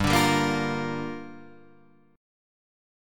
Gm7 chord